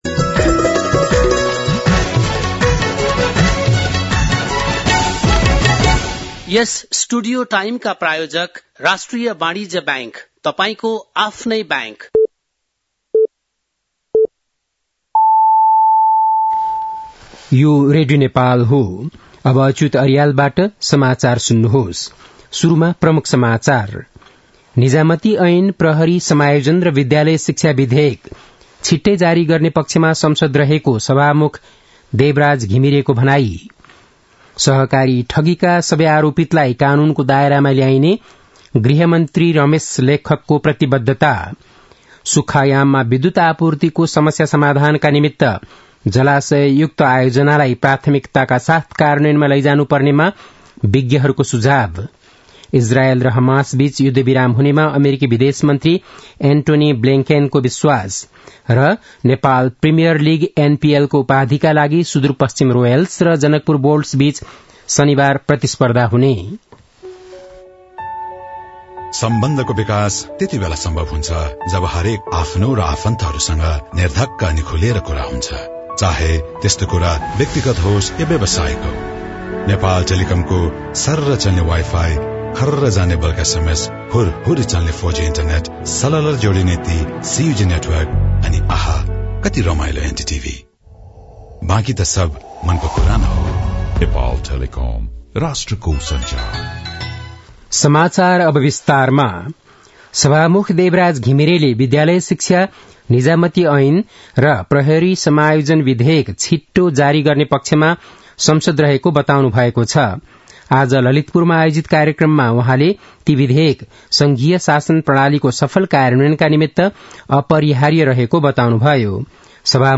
बेलुकी ७ बजेको नेपाली समाचार : ५ पुष , २०८१
7-pm-nepali-news-9-04.mp3